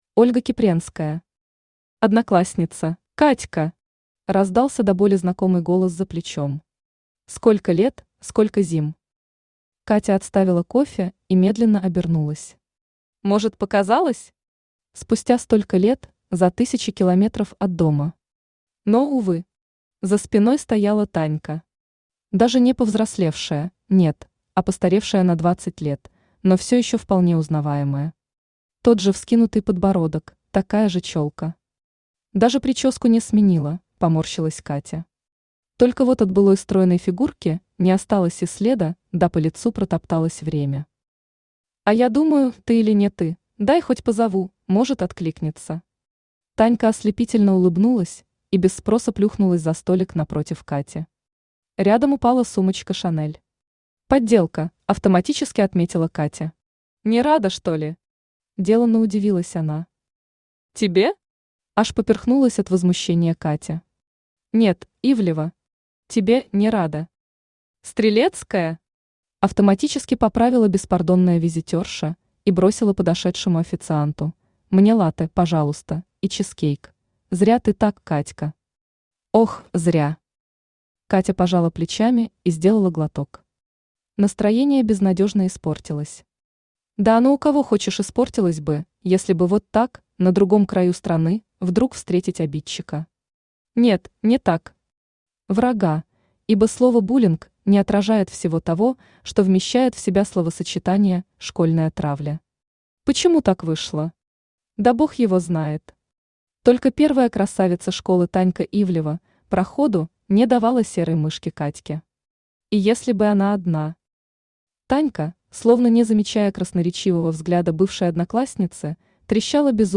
Аудиокнига Одноклассница | Библиотека аудиокниг
Aудиокнига Одноклассница Автор Ольга Кипренская Читает аудиокнигу Авточтец ЛитРес.